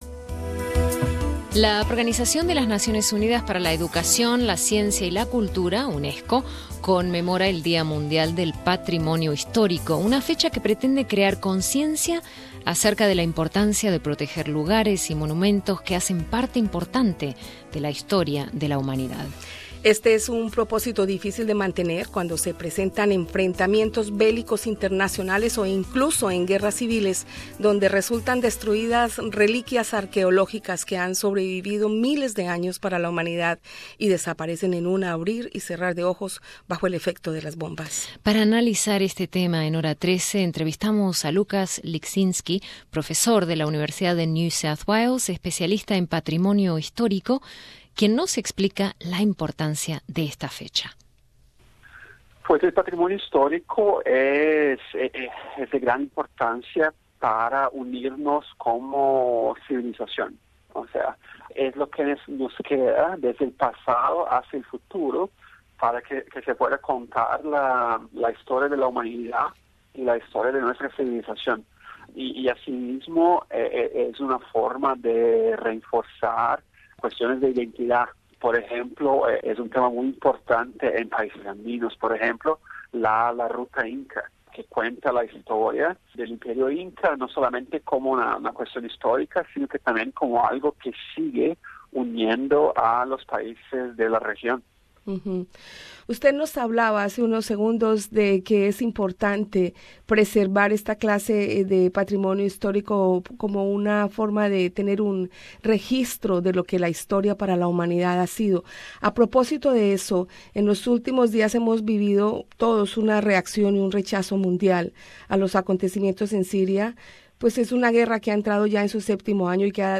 La UNESCO celebra el 18 de Abril el día mundial del Patrimonio Histórico. Este año la fecha se ha dedicado al turismo ecológico. Es también un día para tomar conciencia sobre el daño que los conflictos bélicos infligen a dichos sitios y monumentos. Entrevista